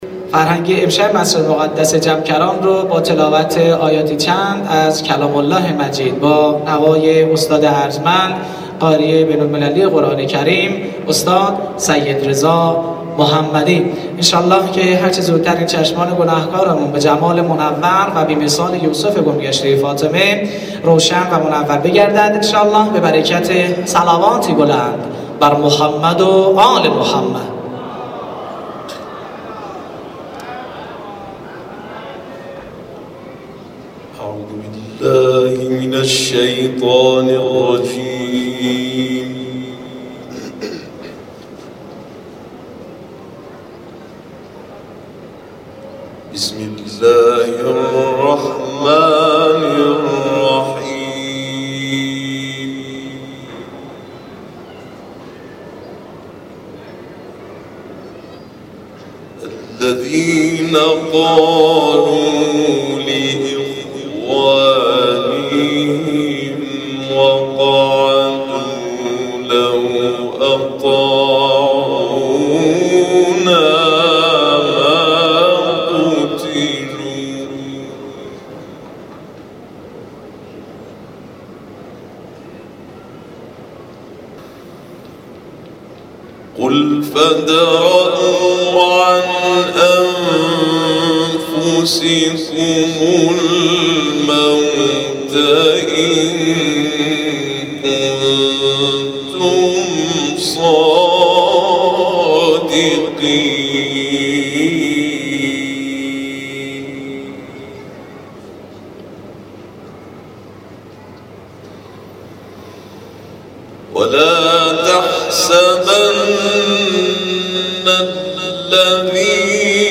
ال عمران-تلاوت جمکران.mp3
ال-عمران-تلاوت-جمکران.mp3